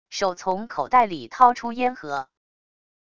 手从口袋里掏出烟盒wav音频